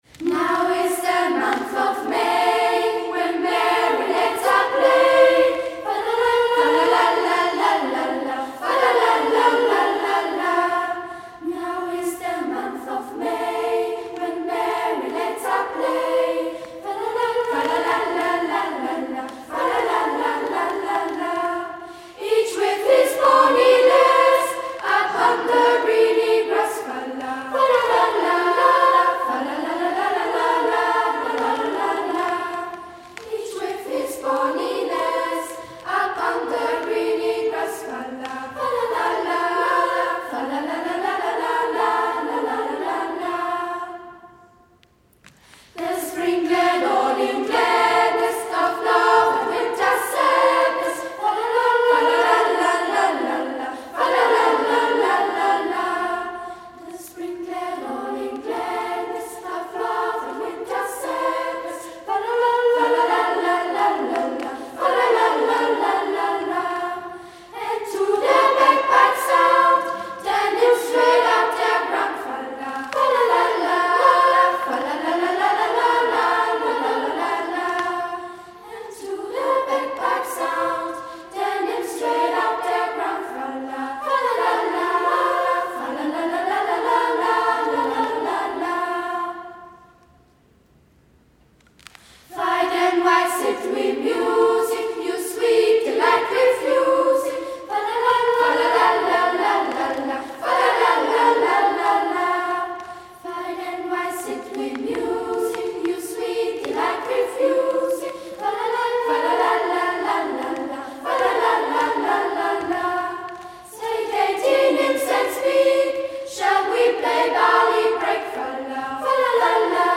Die Schülerinnen der 2. und 3. Klasse trugen drei Lieder vor: